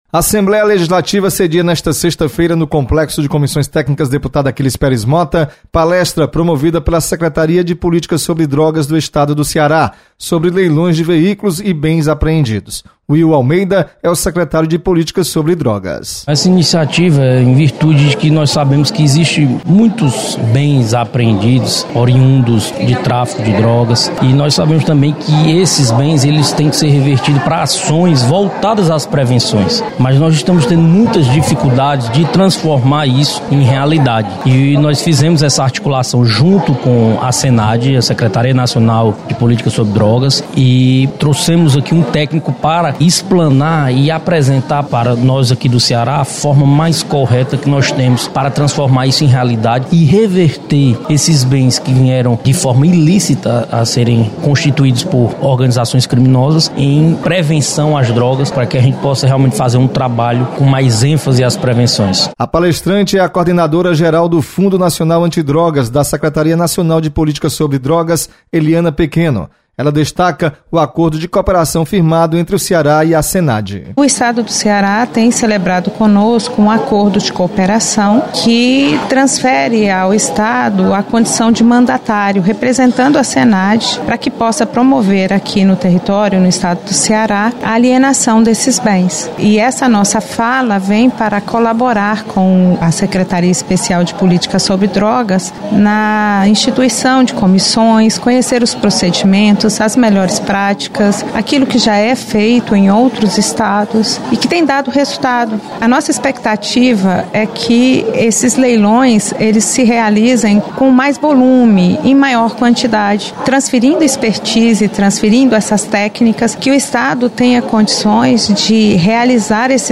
Você está aqui: Início Comunicação Rádio FM Assembleia Notícias Palestra